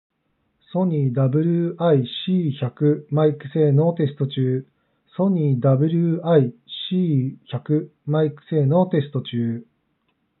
少しこもり気味のマイク性能。